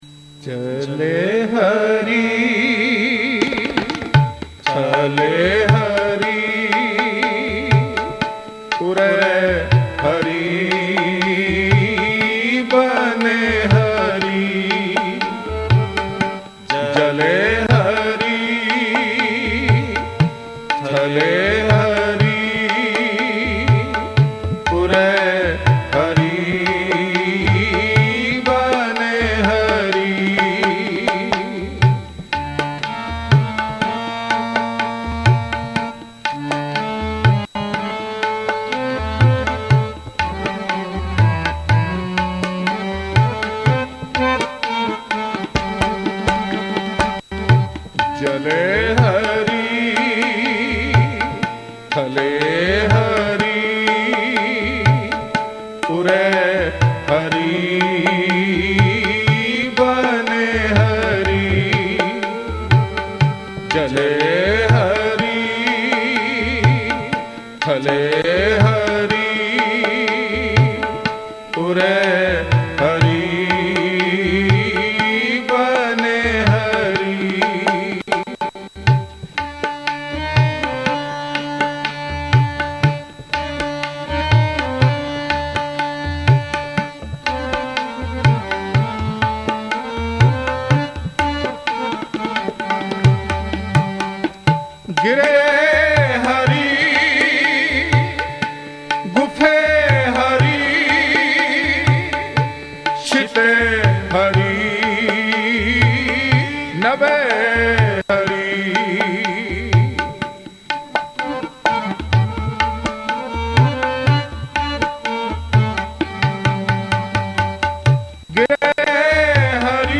Hazoori Ragi Sri Darbar Sahib